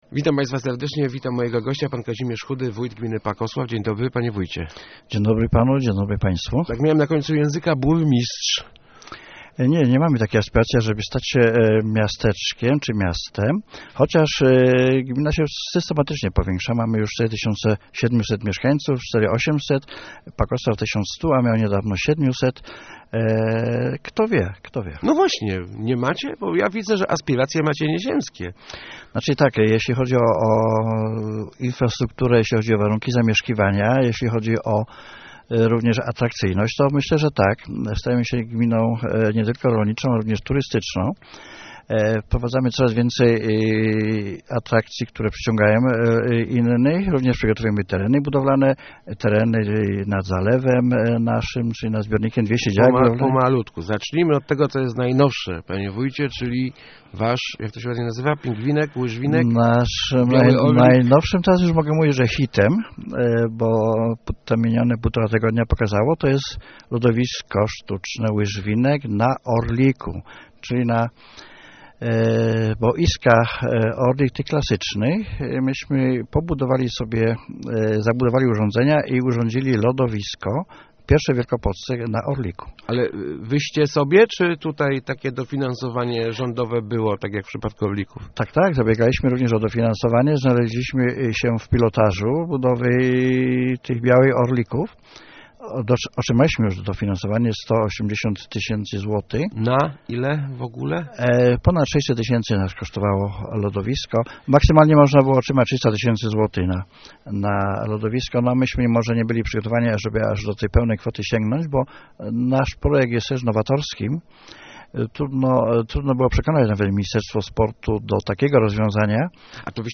Lodowisko na "Orliku" to nasz własny, autorski pomysł - mówił w Rozmowach Elki wójt Pakosławia Kazimierz Chudy. "Łyżwinek" okazał się wielkim sukcesem, technologią zainteresowane jest już wiele gmin z całej Polski.